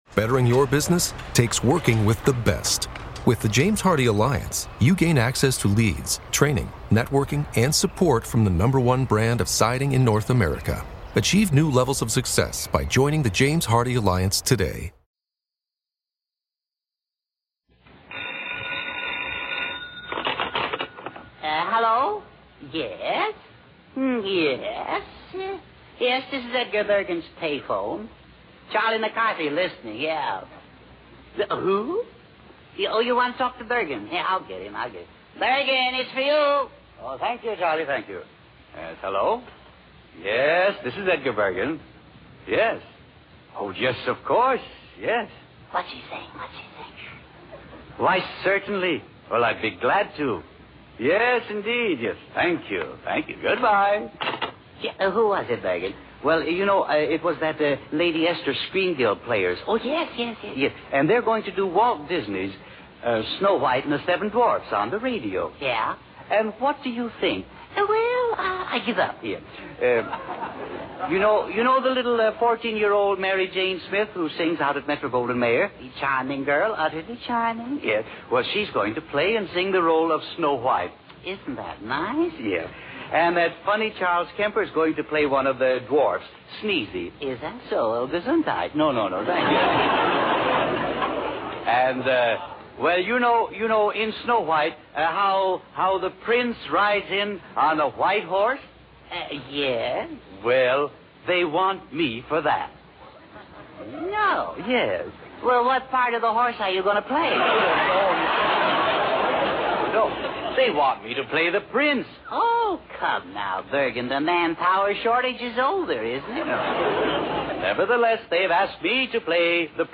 These enchanting old-time Christmas radio shows include heartwarming comedies and captivating dramas that transport listeners to a bygone era, filling their hearts with the spirit of the season.